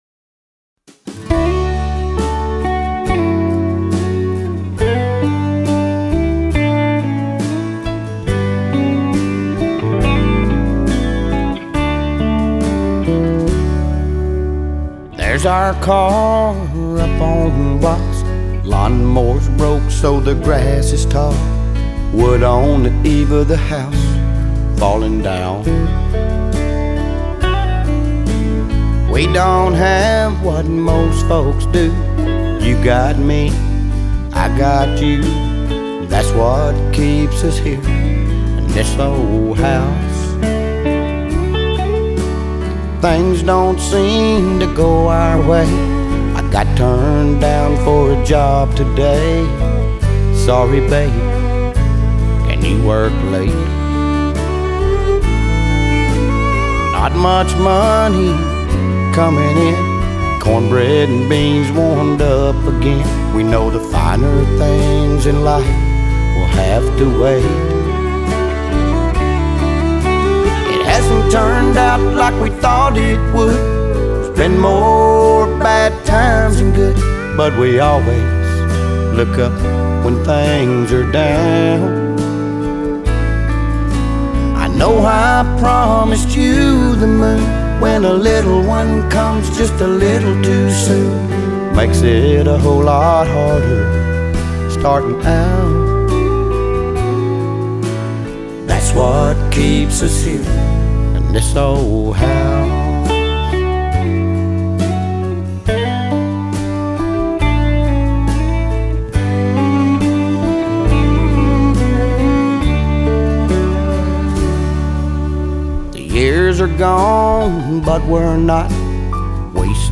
We play New Country, Classic Country, Texas Country
recorded at Cherryridge Studio in Floresville Texas